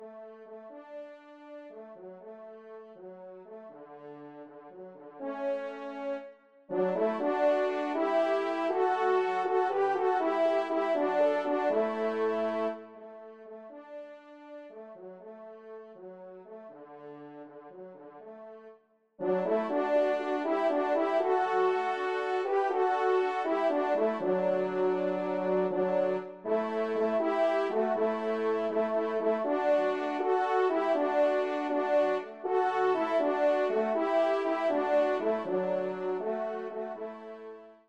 2e Trompe